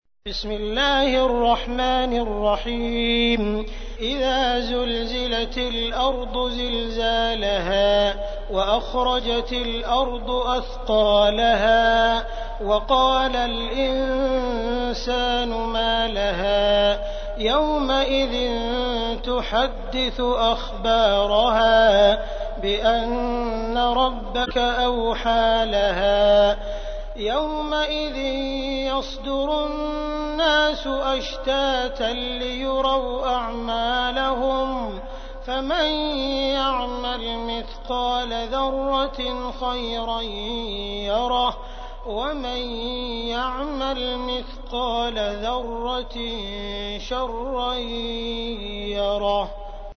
تحميل : 99. سورة الزلزلة / القارئ عبد الرحمن السديس / القرآن الكريم / موقع يا حسين